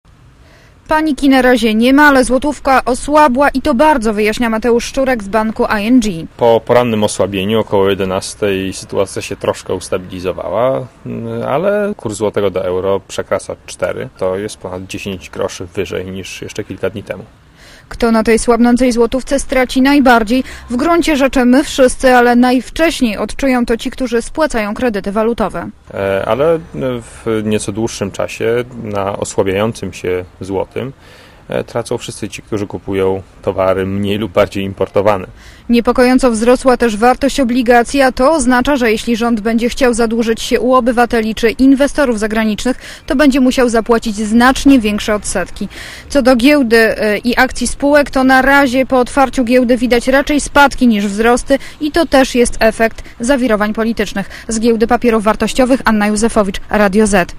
Źródło zdjęć: © Archiwum 27.10.2005 | aktual.: 27.10.2005 12:32 ZAPISZ UDOSTĘPNIJ SKOMENTUJ Relacja reportera Radia ZET